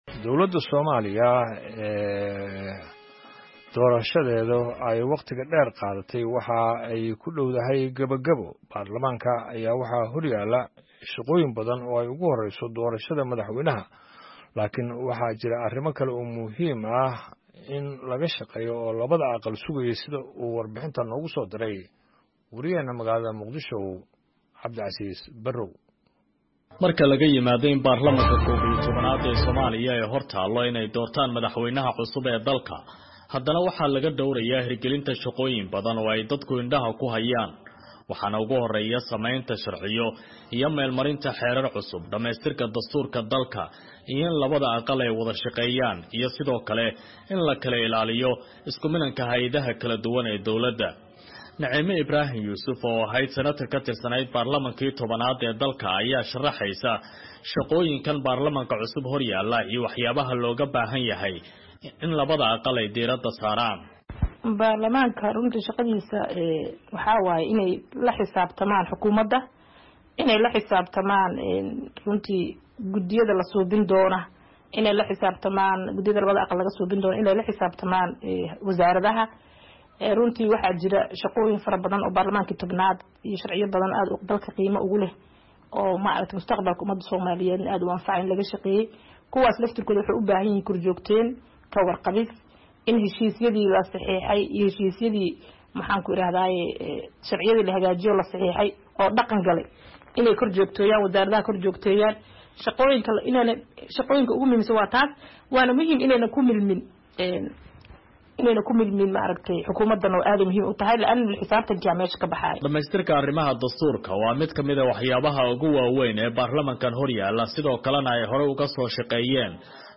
Warbixintaan